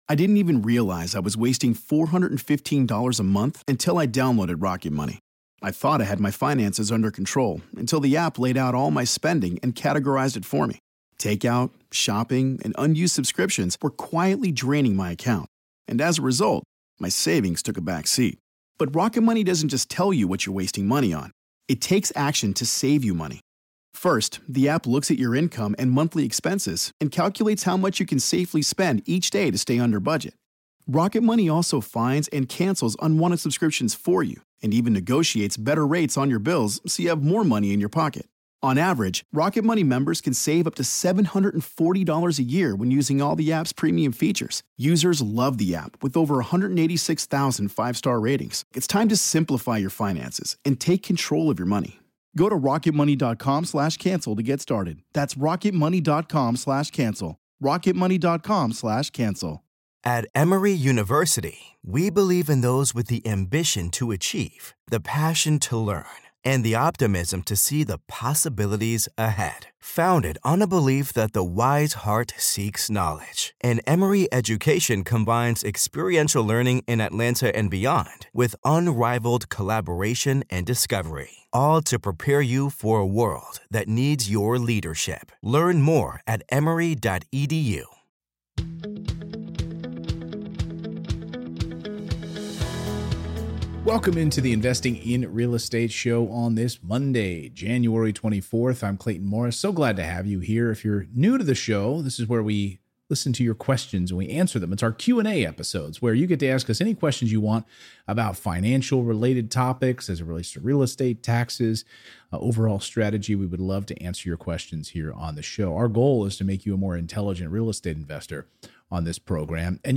If you have untapped home equity you want to access in order to invest, what should your first step be? That's the first question I'm answering on this Q&A episode of Investing in Real Estate!